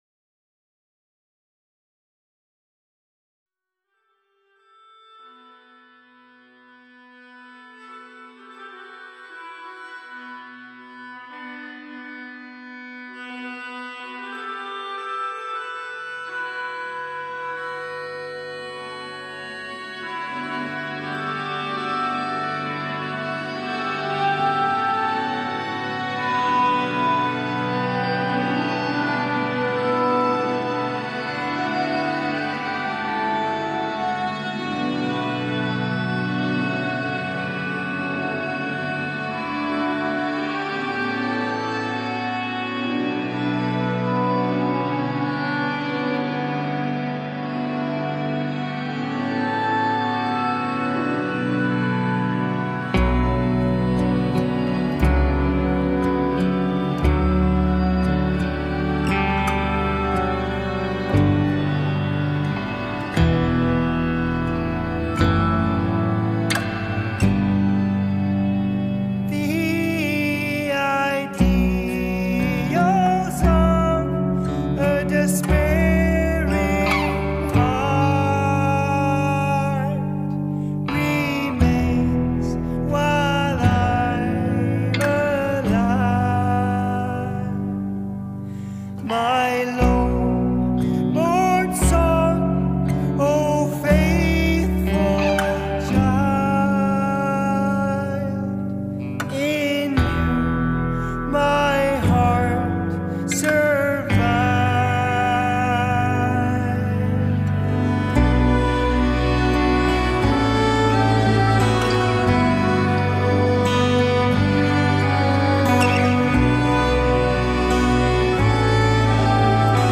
آلترناتیو راک
آلترناتیو متال